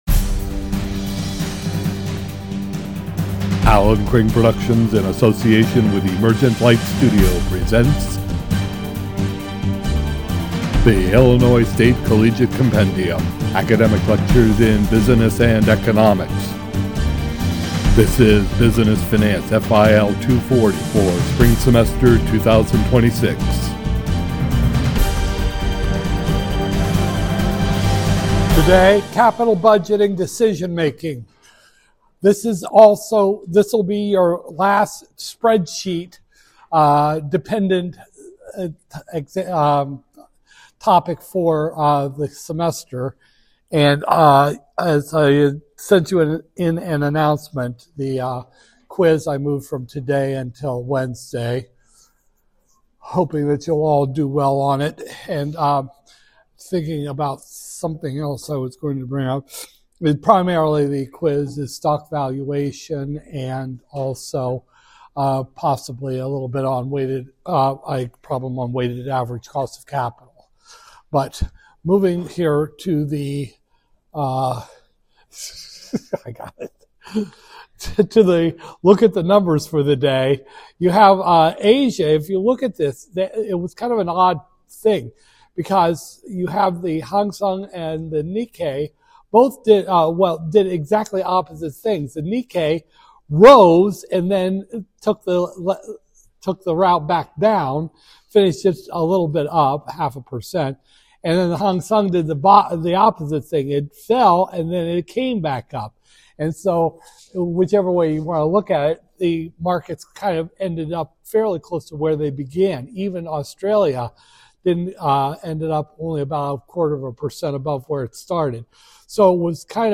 Business Finance, FIL 240-002, Spring 2026, Lecture 21